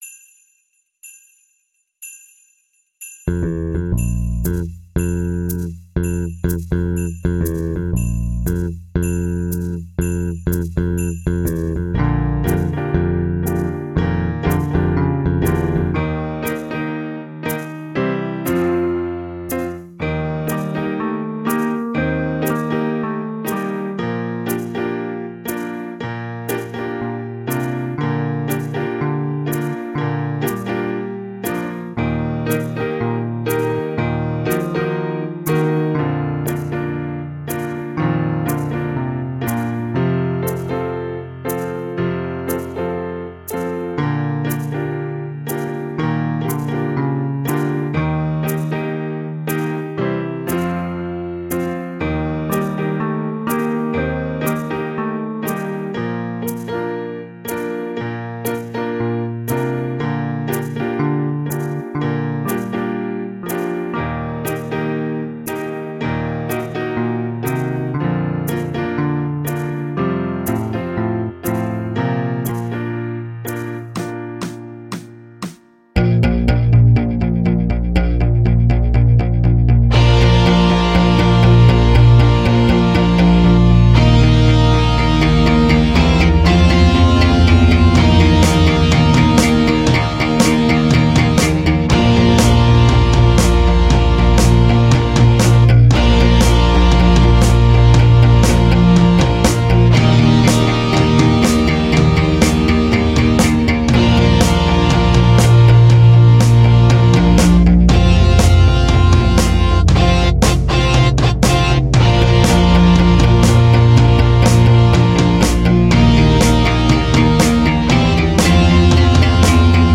Així les podeu cantar com si fos un karaoke!!!